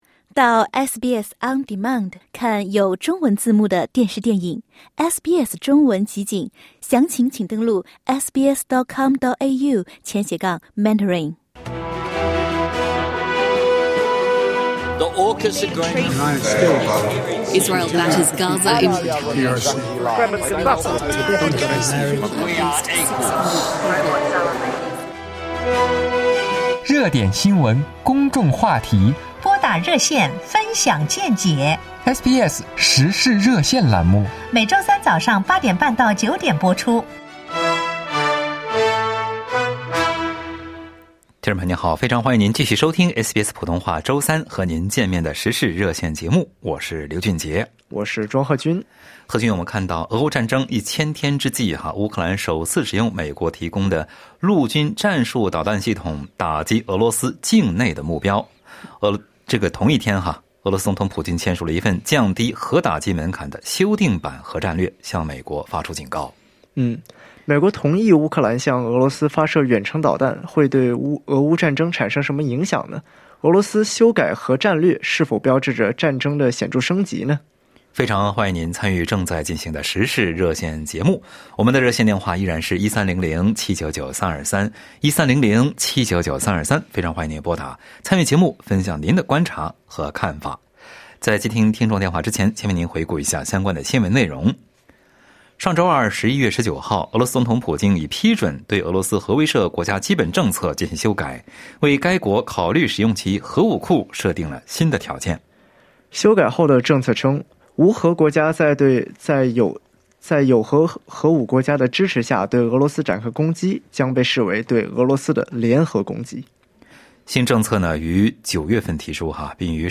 SBS普通话《时事热线》节目听友们就此分享了看法。